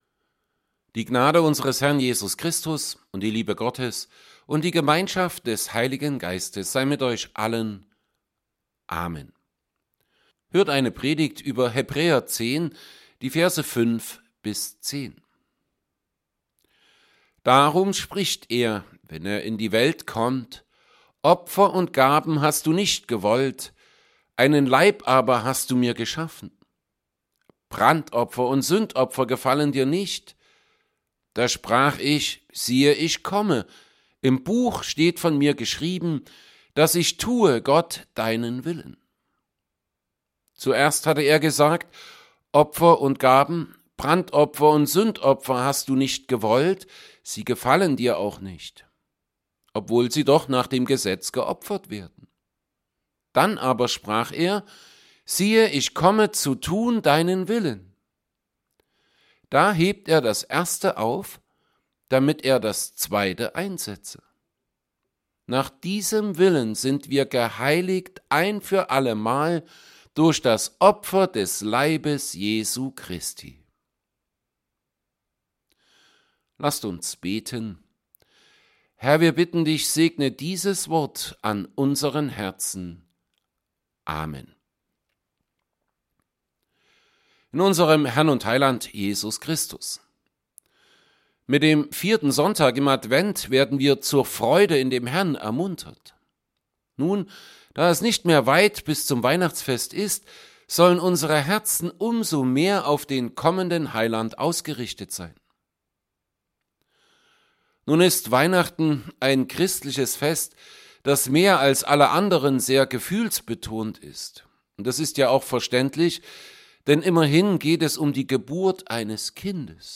Sonntag im Advent , Predigt , St. Paulusgemeinde Saalfeld « 3.
Predigt_zu_Hebräer_10_5b10.mp3